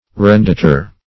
Arendator \Ar`en*da"tor\, n. [LL. arendator, arrendator, fr.